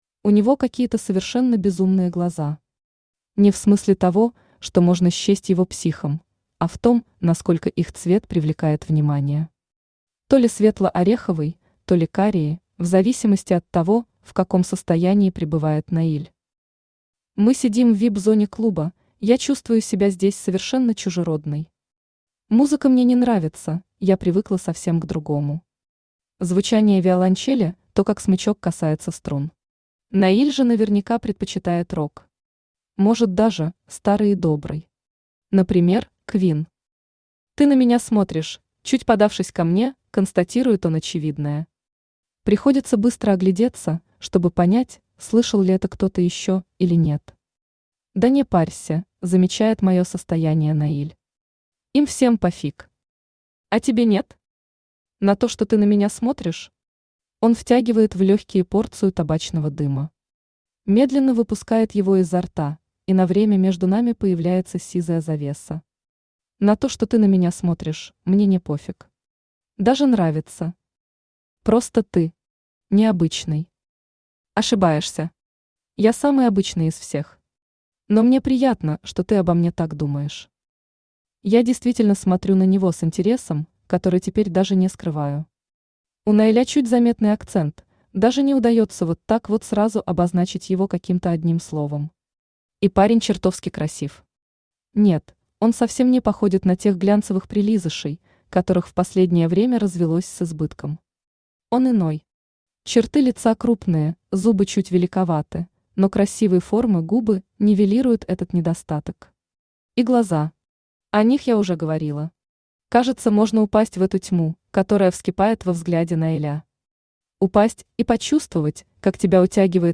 Aудиокнига Чужая жена Автор Полина Рей Читает аудиокнигу Авточтец ЛитРес.